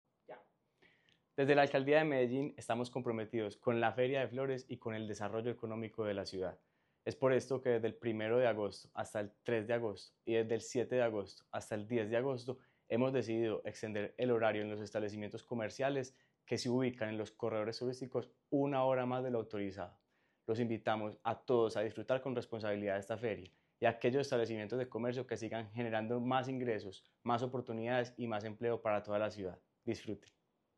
Busca impulsar la economía local, con una proyección de impacto económico superior a los 50 millones de dólares. Declaraciones del subsecretario de creación y fortalecimiento empresarial de Medellín, Juan David Molina Olarte.
Declaraciones-del-subsecretario-de-creacion-y-fortalecimiento-empresarial-de-Medellin-Juan-David-Molina-Olarte.-1.mp3